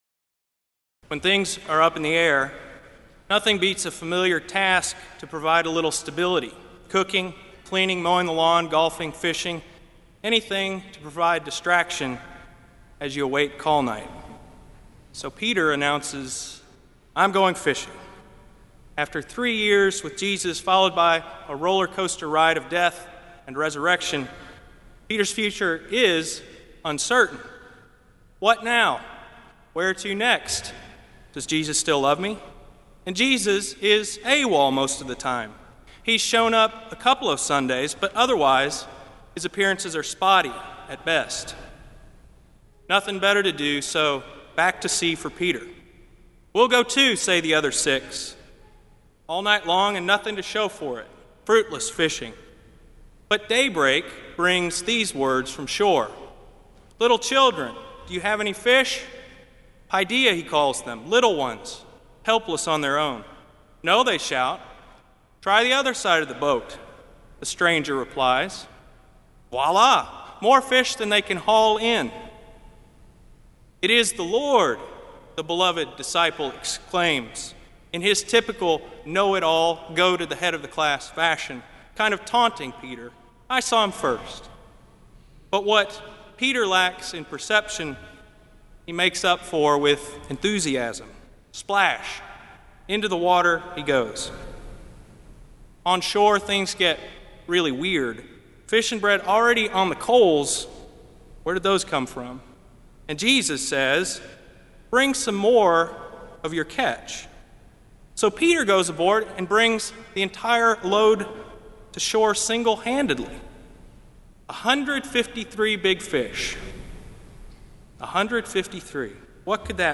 Kramer Chapel Sermon - April 21, 2006